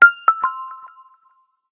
alert.ogg